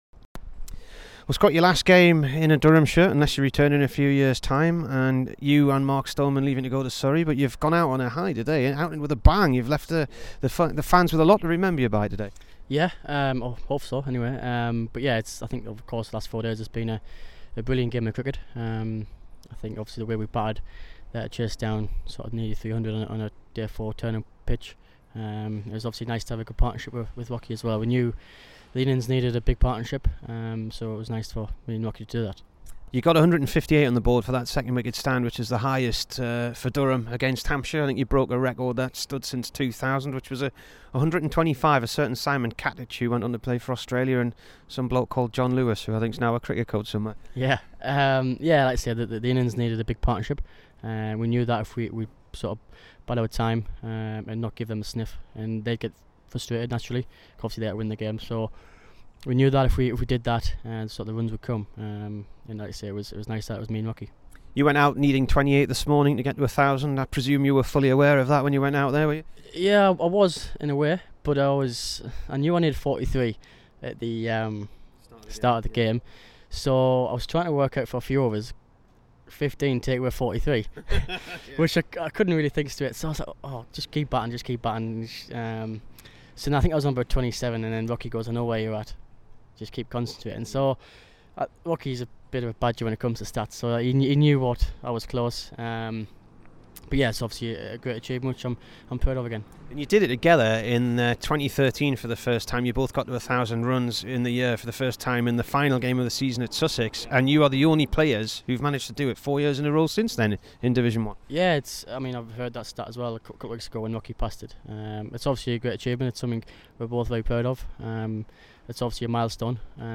Scott Borthwick int
Here's the Durham all rounder after his 88 in his final innings at Hants.